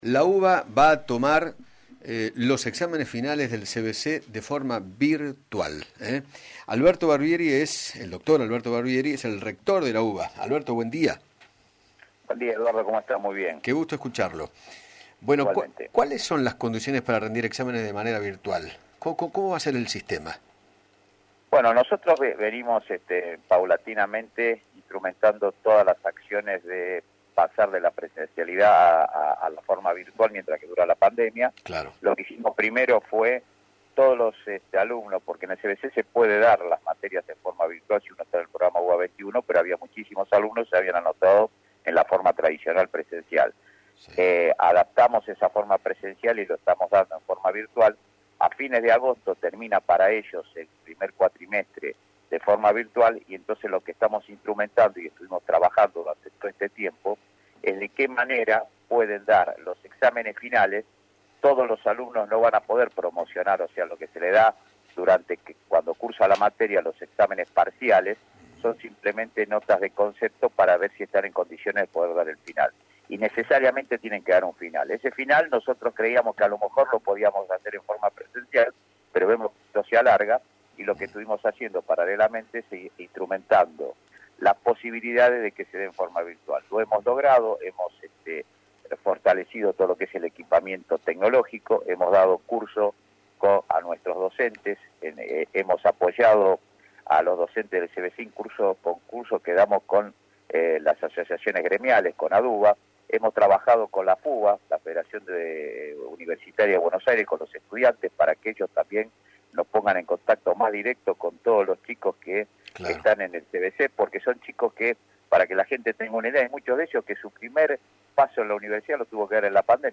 Alberto Barbieri, rector de la UBA, dialogó con Eduardo Feinmann sobre la forma en que serán los finales del CBC en la Universidad de Buenos Aires y explicó por qué cambiaron la modalidad: “pensamos que los finales podían darse presenciales, pero vemos que esto se alarga”.